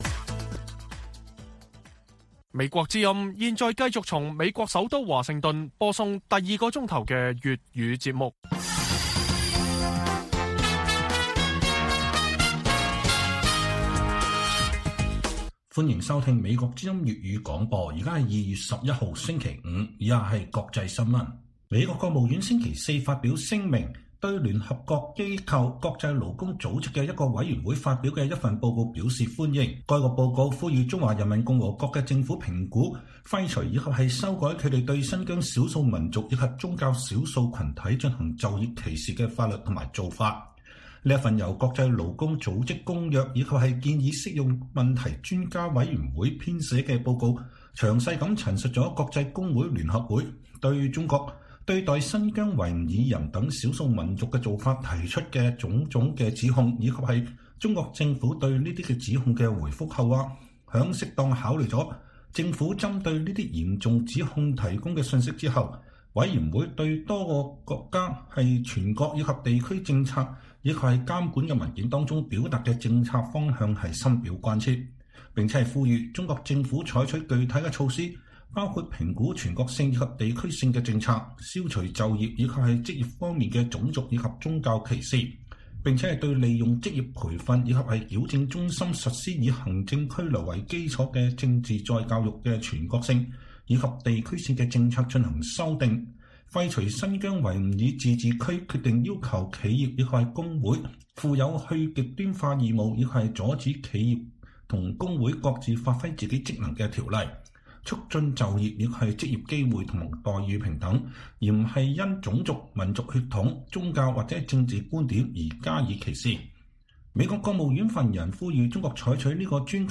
粵語新聞 晚上10-11點 : 批評還是讚揚？北京冬奧牆內牆外各說各話